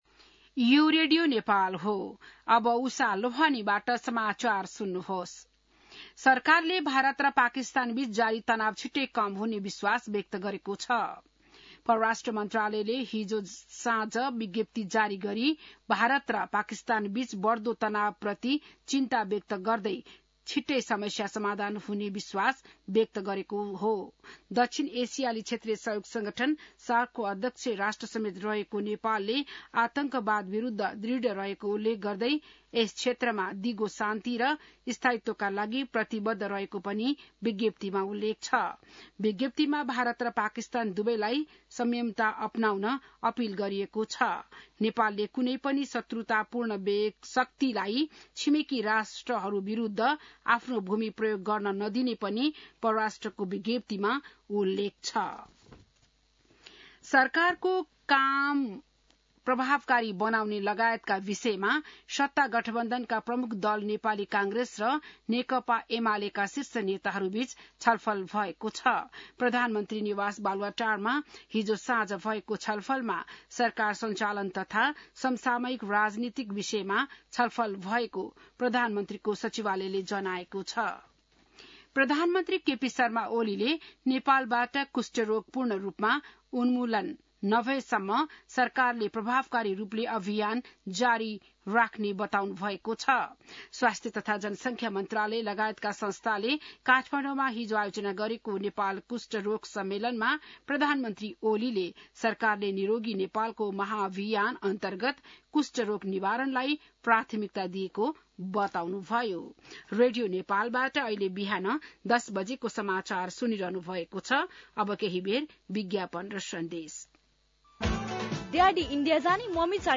बिहान १० बजेको नेपाली समाचार : २६ वैशाख , २०८२